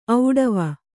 ♪ auḍava